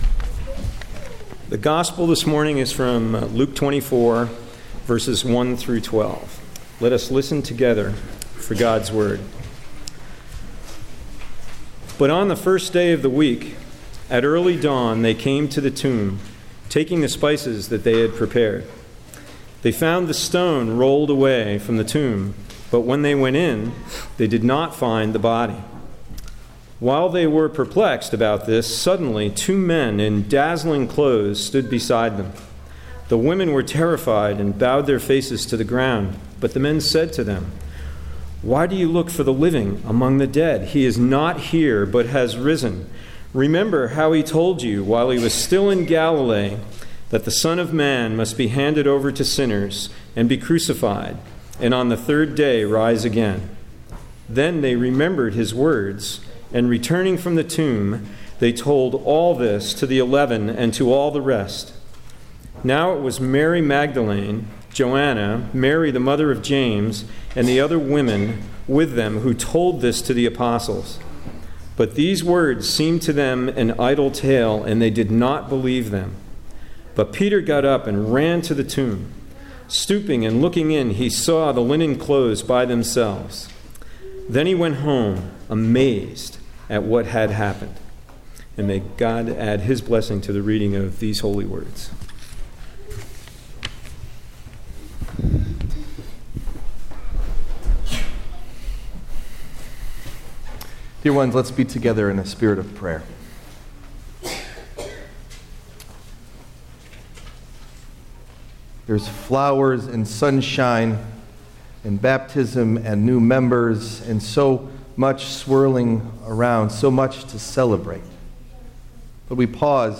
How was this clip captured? Date: March 31st, 2013 (Easter) Message Delivered at: The United Church of Underhill (UCC and UMC)